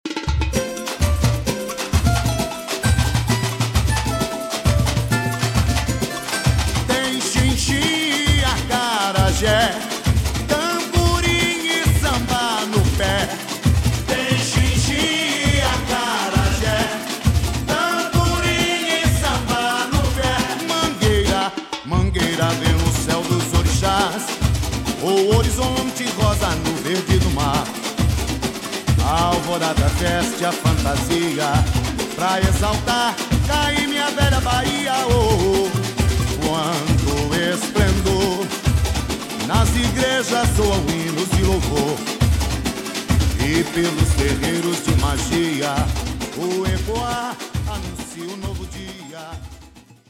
Samba Enredo